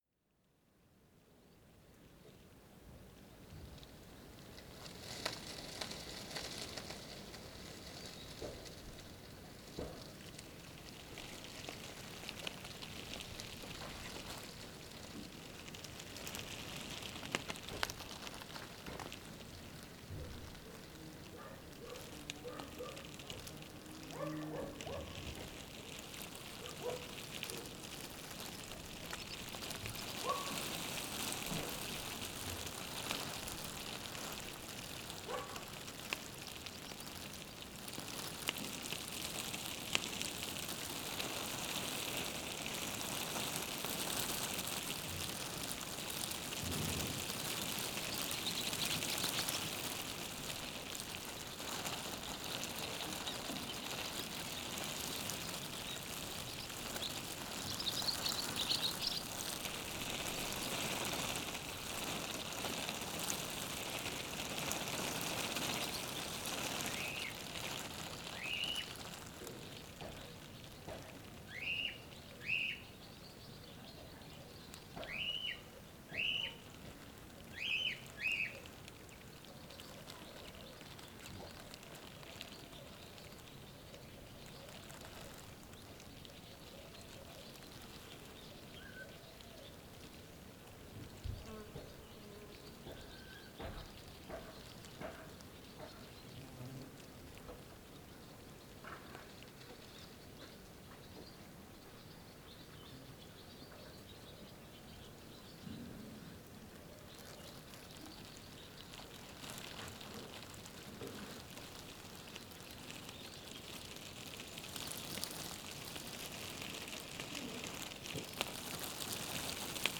Paisagem sonora de vento sobre sacos de plástico em sementeira na Rua da Capela em Zonho, Côta a 11 Março 2016.